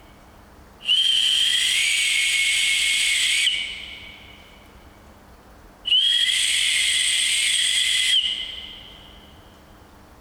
A modest puff in a shop may produce a good clean sound, giving it a good blast with the intention of alerting someone simply overwhelms the chamber and results in nothing discernible.
Lifeventure whistle